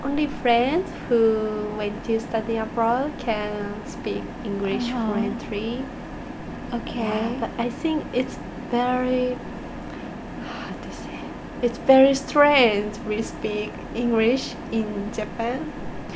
S1 = Brunei female S2 = Japanese female Context: S2 is talking about whether she will have opportunities to speak English when she goes back to Japan.
Intended Word: fluently Heard as: poetry Discussion: Both [l]s in fluently are pronounced as [r].